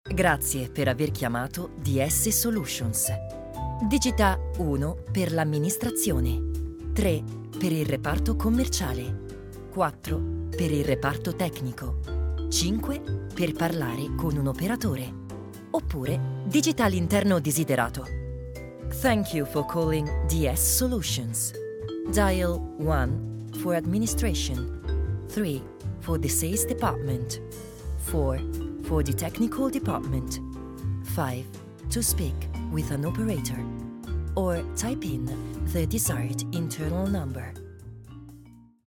Commercieel, Stoer, Veelzijdig, Vriendelijk, Warm
Telefonie